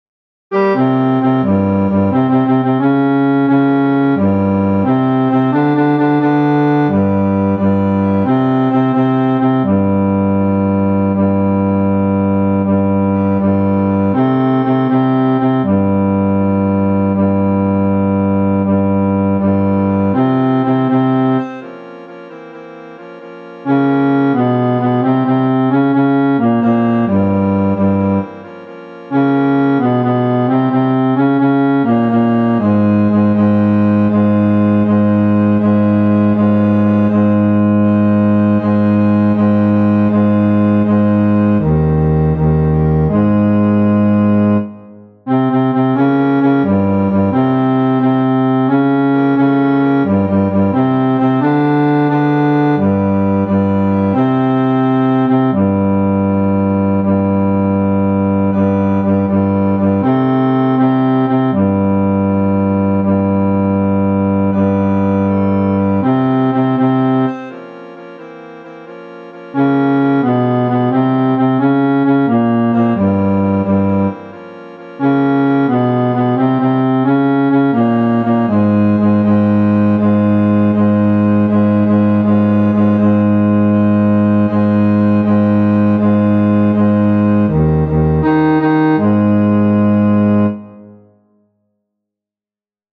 FF:HV_15b Collegium musicum - mužský sbor
Krasneocitve-Bas.mp3